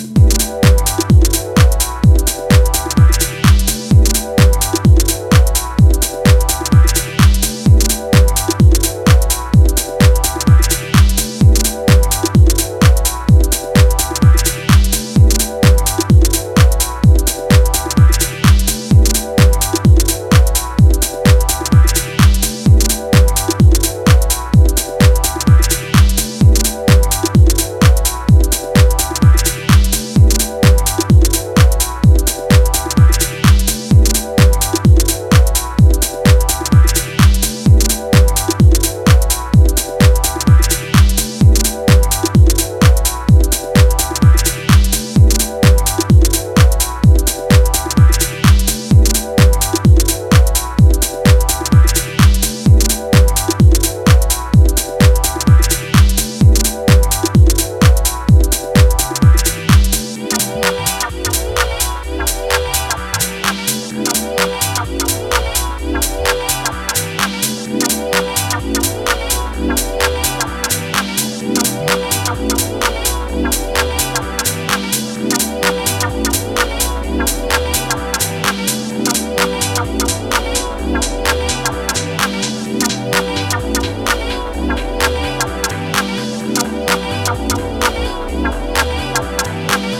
ジャンル(スタイル) DEEP HOUSE / TECH HOUSE / MINIMAL